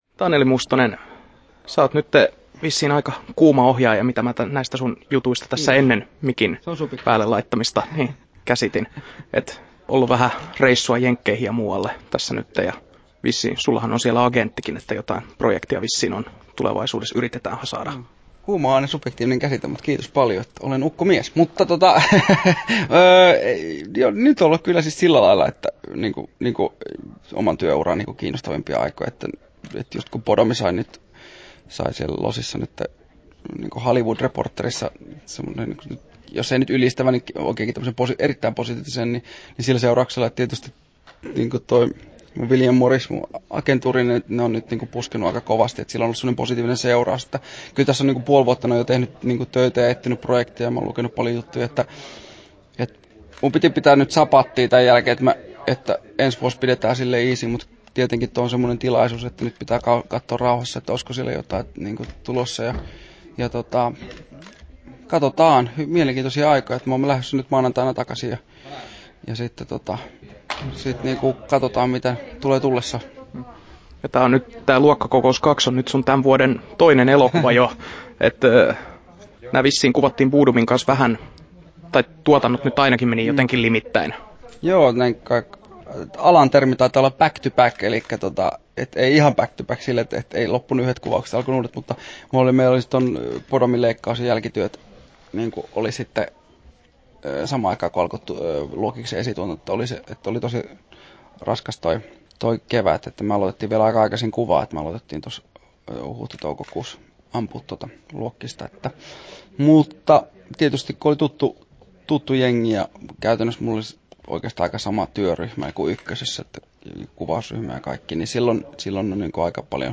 Haastattelussa Taneli Mustonen Kesto